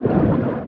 Sfx_creature_pinnacarid_swim_fast_07.ogg